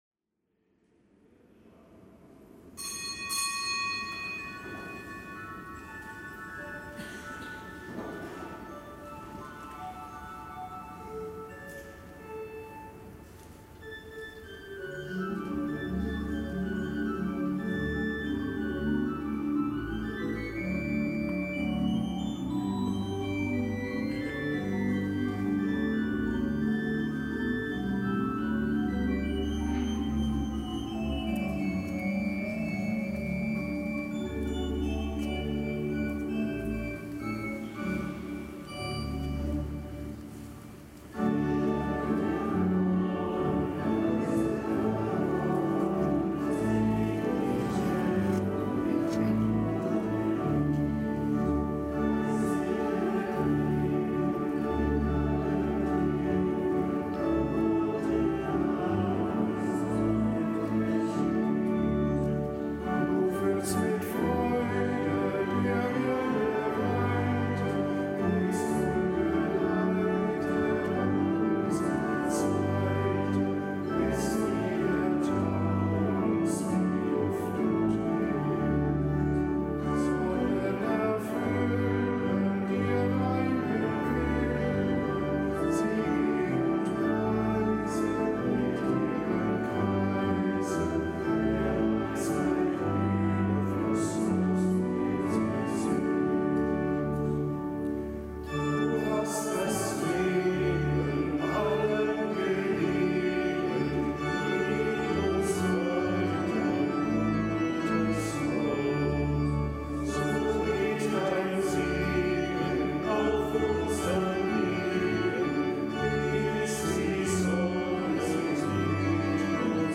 Kapitelsmesse am Freitag der fünfzehnten Woche im Jahreskreis